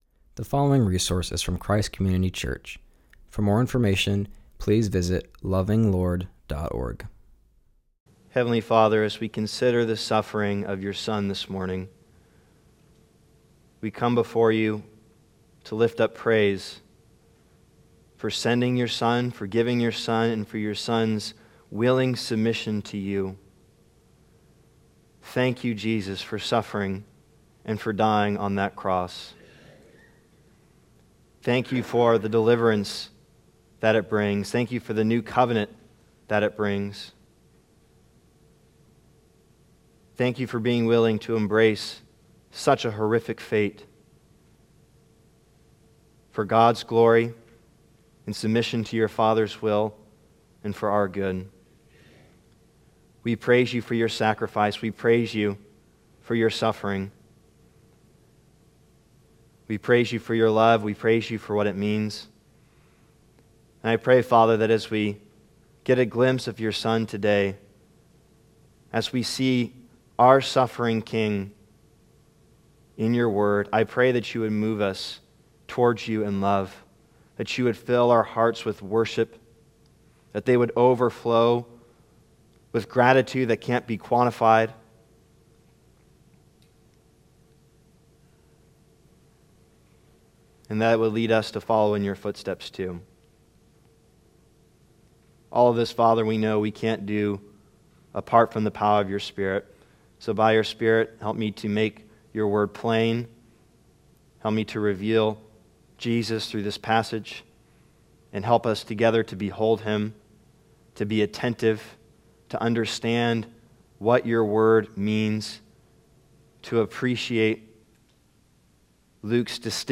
continues our study in Luke by preaching on Luke 22-23.